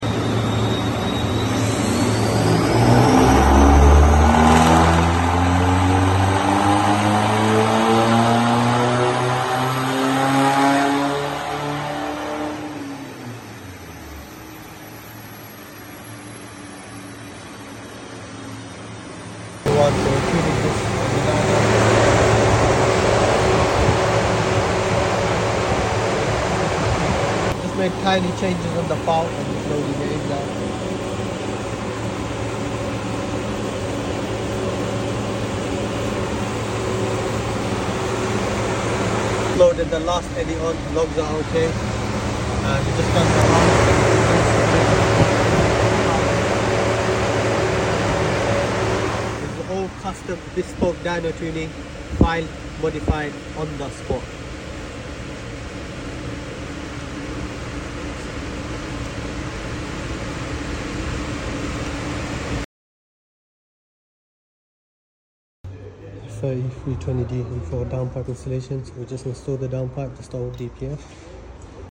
F30 320d b47 185hp stock on the dyno. previously had backbox and mid box delete, it came in to us for downpipe installation and stage 1 performance dyno tuning session.